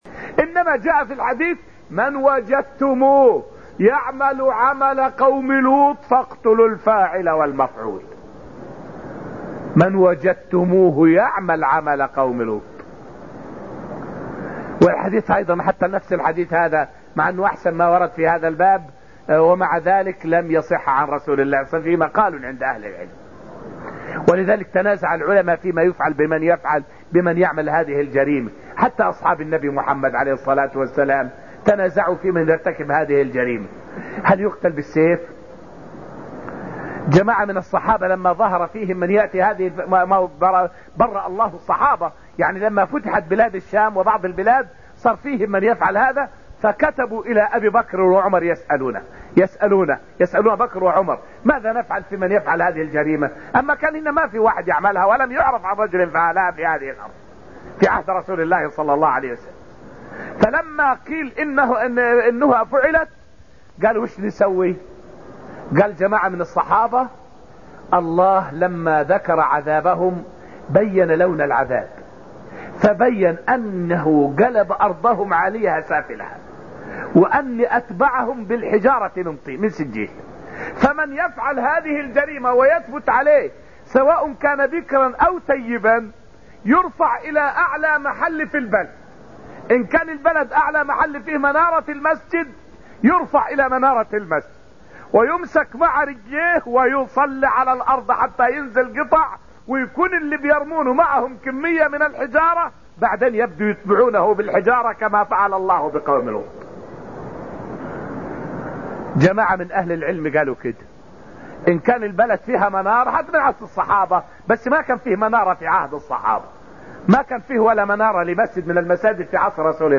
فائدة من الدرس الثامن من دروس تفسير سورة القمر والتي ألقيت في المسجد النبوي الشريف حول أقوال العلماء في من يعمل عمل قوم لوط.